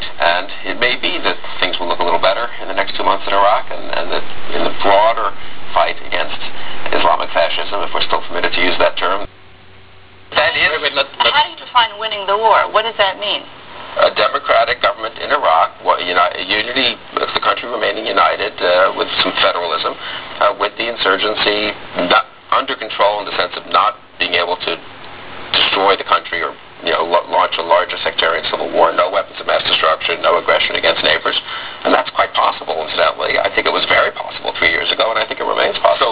Bill Kristol on Fox News Sunday:
At one point during the roundtable, Kristol said this:
small low-fi 300K wav file of Kristol's remarks (8KHz, 8-bit, mono).